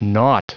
Prononciation du mot nought en anglais (fichier audio)
Prononciation du mot : nought